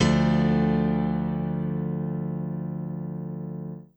BAL Piano Chord 1 G.wav